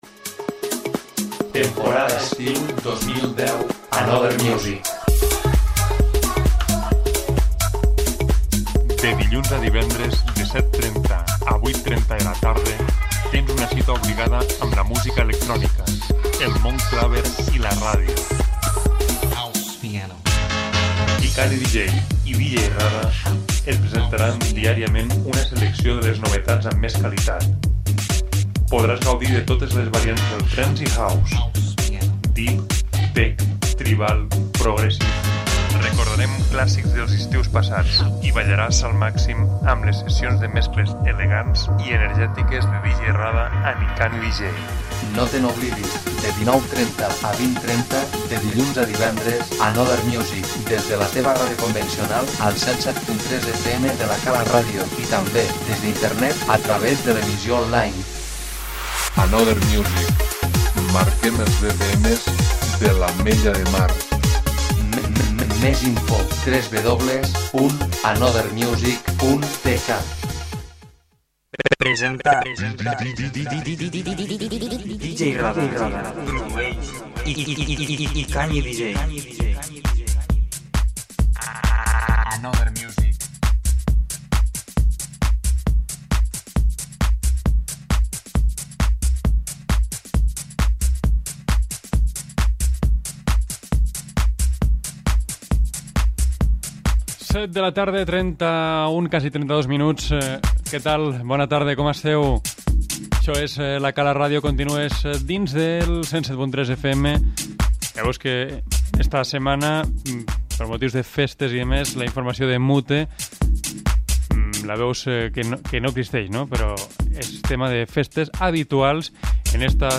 amb una selecció carregada de novedosos sonsTrance.